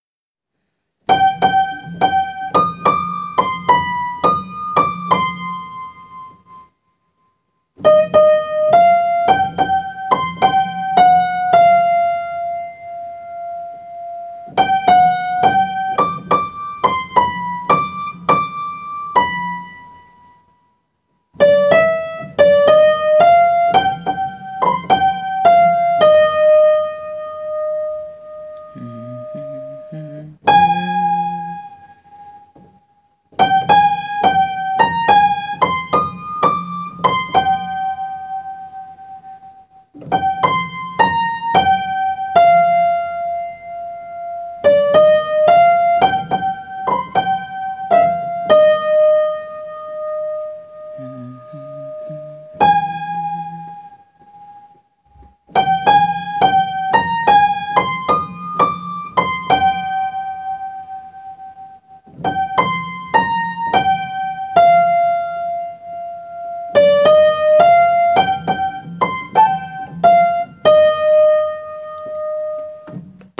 les sons pour apprendre, par vos cheffes préférées